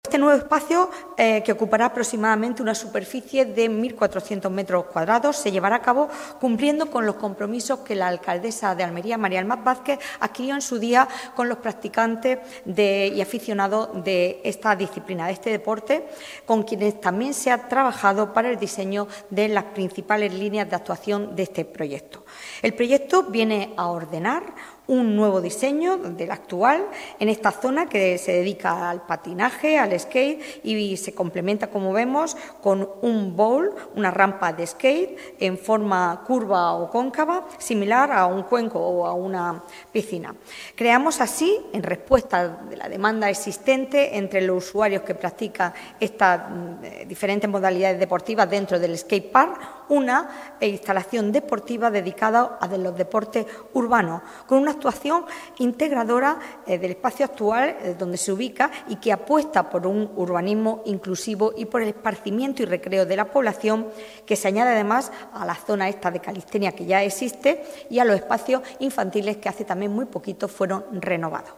La portavoz del Equipo de Gobierno municipal, Sacramento Sánchez, ha explicado en rueda de prensa el contenido de los acuerdos adoptados en la última Junta de Gobierno, celebrada el pasado viernes, con más de cuarenta puntos incluidos en su Orden del Día, que esta actuación responde al compromiso adquirido por el Ayuntamiento con los usuarios y aficionados a esta disciplina deportiva, con quienes se ha trabajado en el diseño de las principales líneas del proyecto.